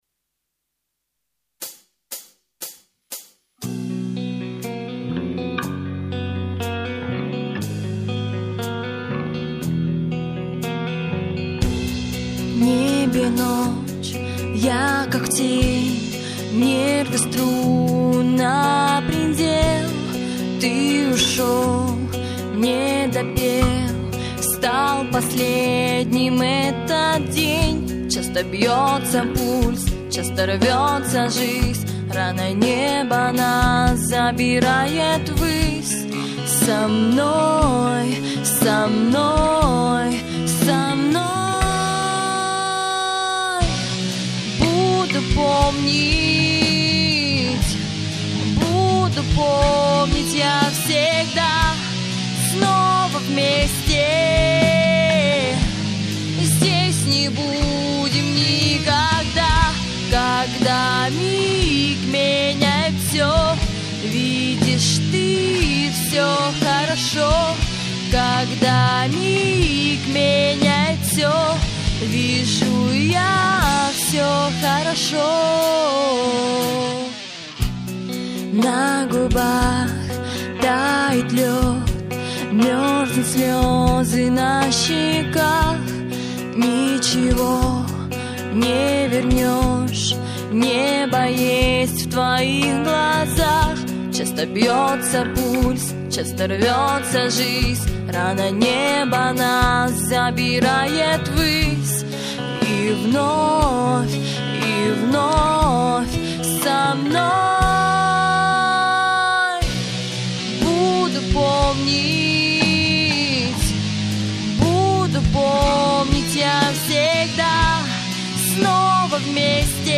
the rock group